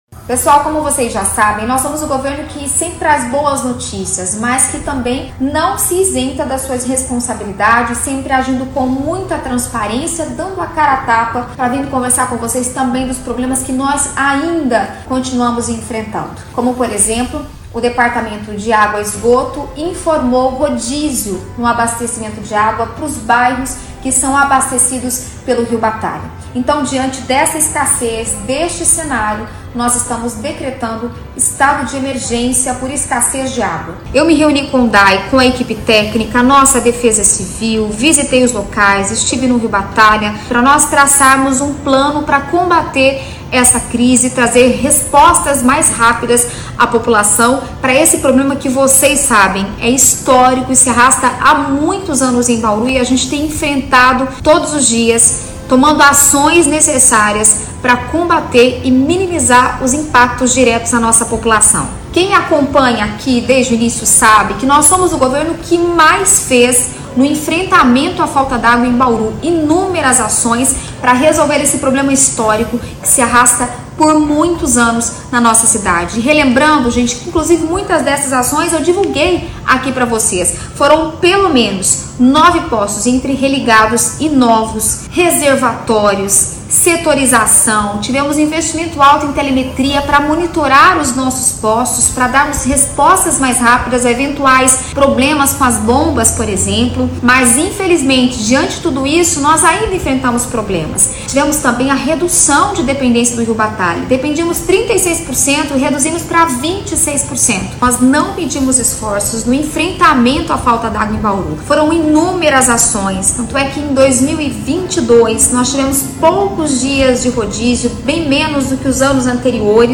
A prefeita Suellen Rosim fala sobre a decretação do estado de emergência: